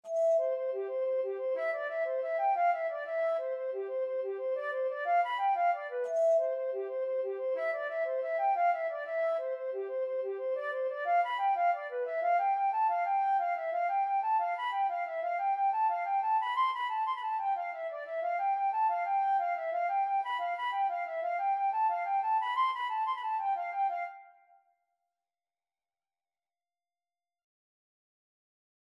C major (Sounding Pitch) (View more C major Music for Flute )
9/8 (View more 9/8 Music)
G5-C7
Flute  (View more Easy Flute Music)
Traditional (View more Traditional Flute Music)